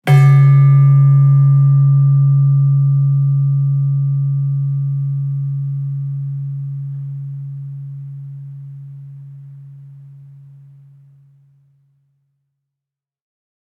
Gender-1-C#2-f.wav